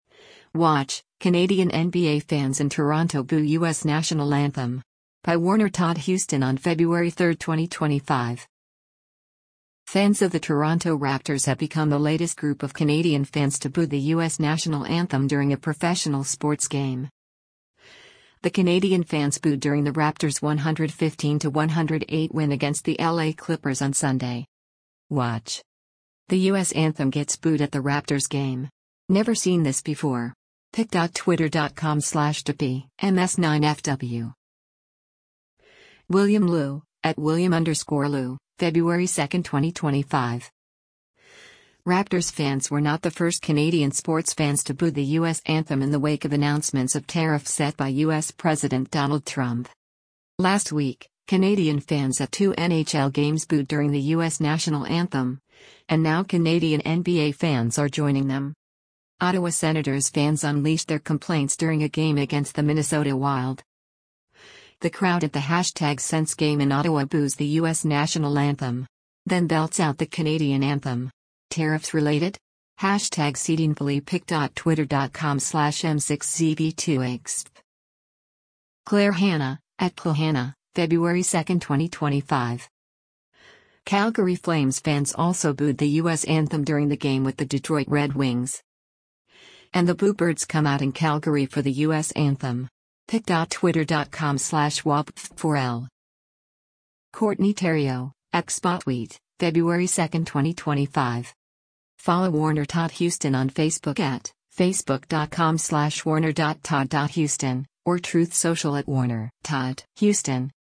WATCH: Canadian NBA Fans in Toronto Boo U.S. National Anthem
Fans of the Toronto Raptors have become the latest group of Canadian fans to boo the U.S. national anthem during a professional sports game.
The Canadian fans booed during the Raptors 115 to 108 win against the L.A. Clippers on Sunday.